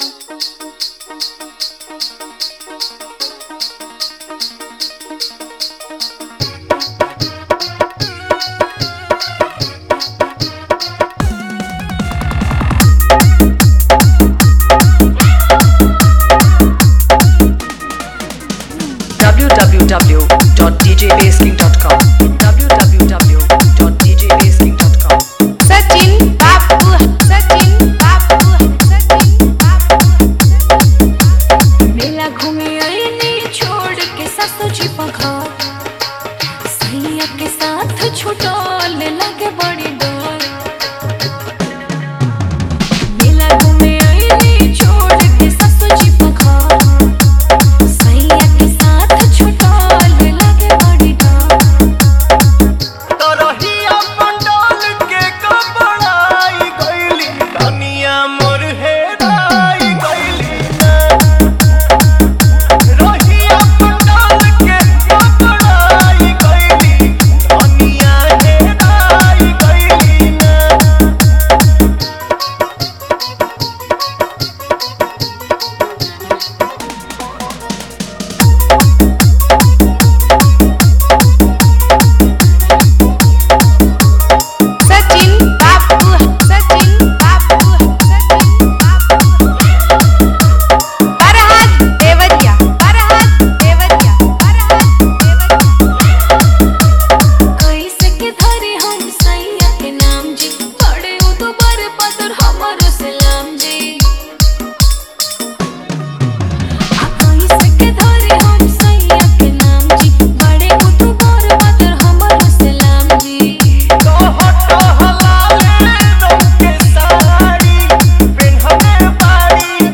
Navratri Dj Remix Songs